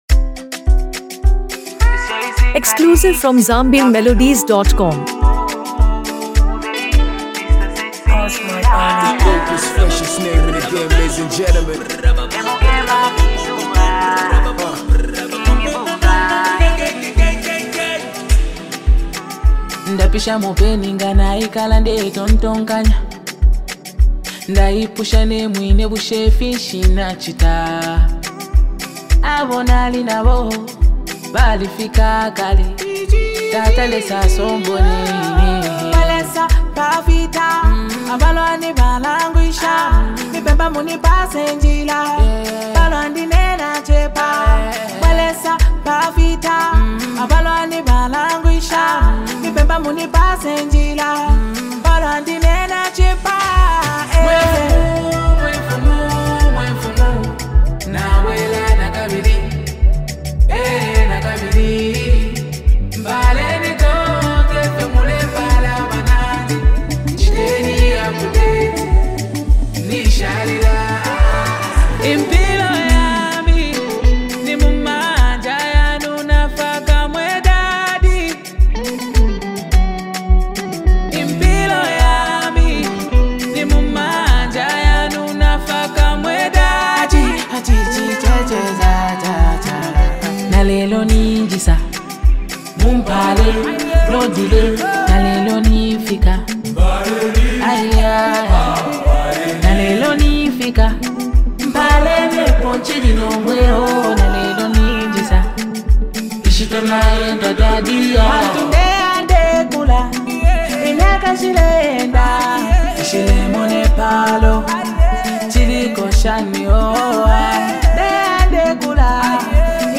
known for his soulful vocals and deep storytelling